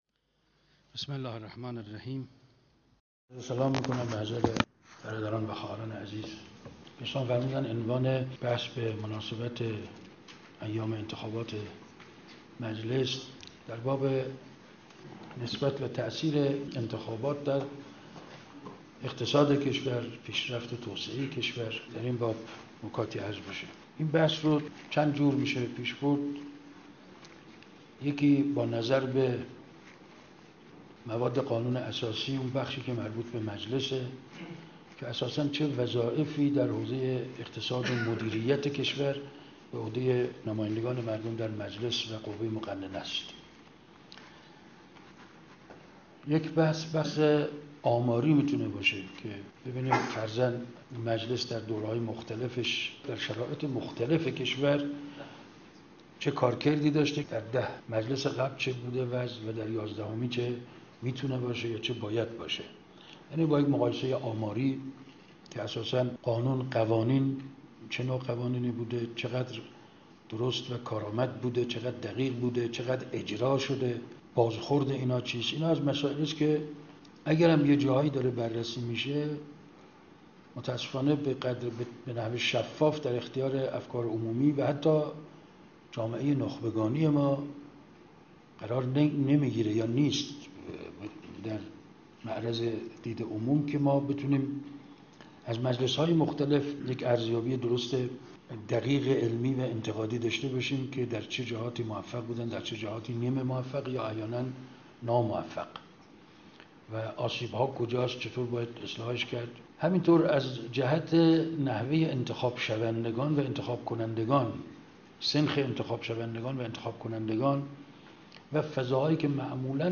نشست (تمدن اسلامی و استعمار غربی)_ دانشگاه کابل _ افغانستان ۱۳۸۷